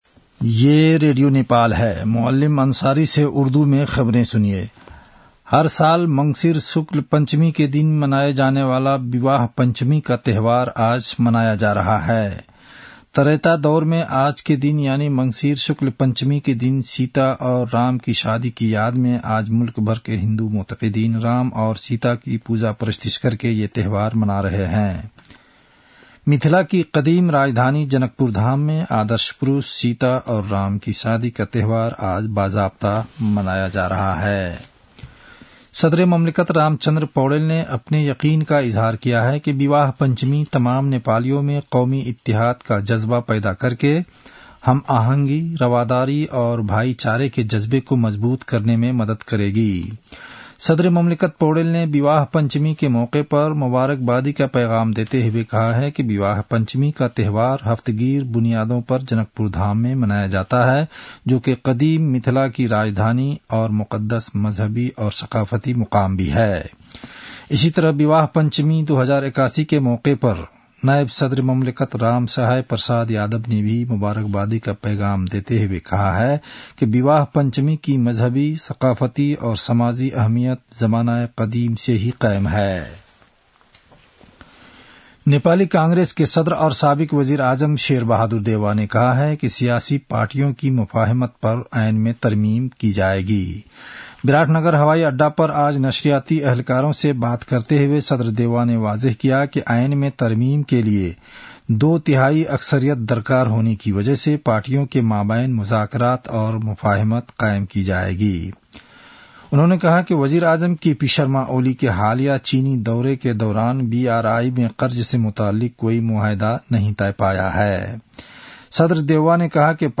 उर्दु भाषामा समाचार : २२ मंसिर , २०८१